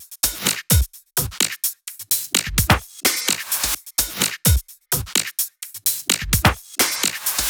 VFH2 128BPM Capone Kit 5.wav